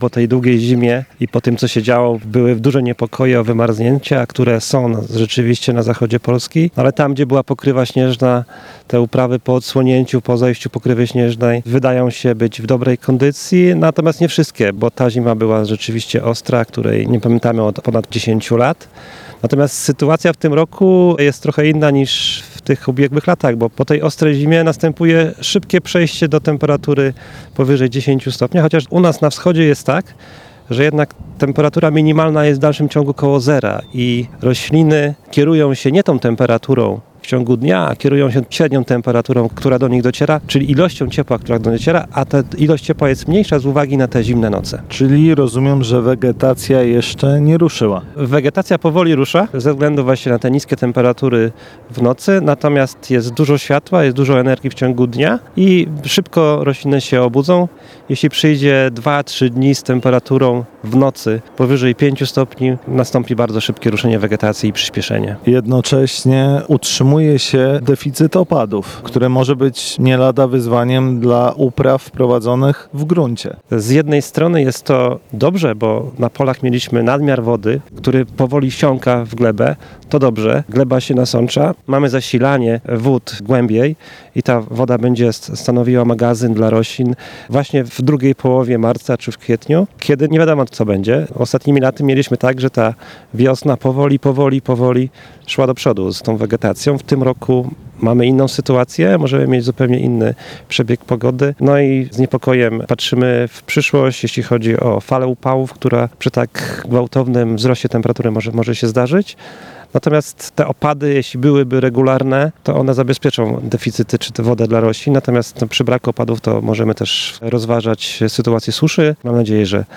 Rozmowa IUNG